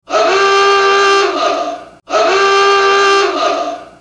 lwsiren-horn.ogg